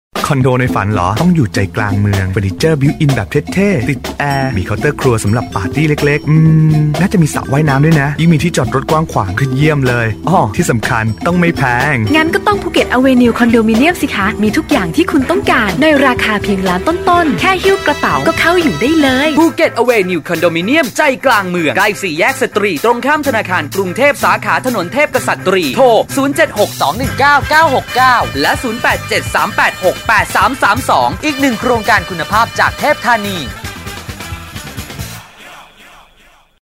นักพากย์ พากเสียง จัดหานักพากย์ งานเสียง พากย์เสียง พากย์เสียงสปอต ลงเสียง นักพากย์ราคาถูก นักพากย์มืออาชีพ ราคาถูก